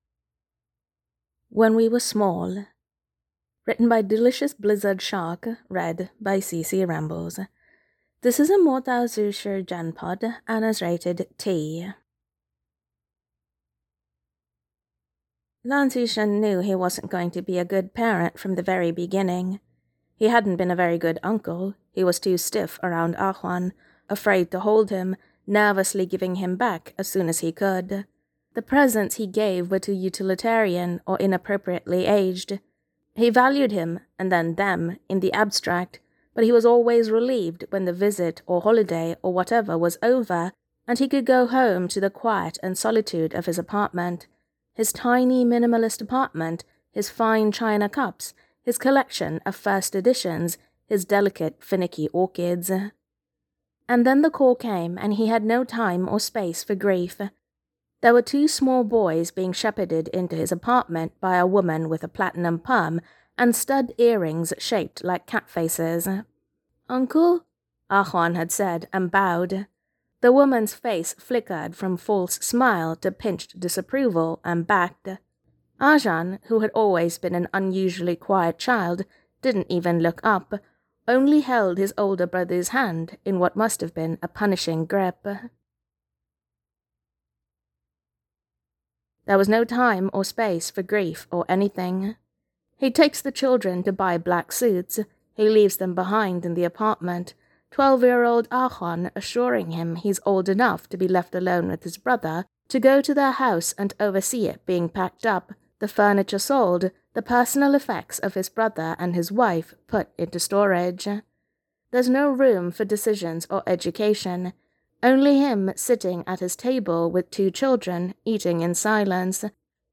[Podfic] When we were small